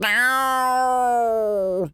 cat_scream_05.wav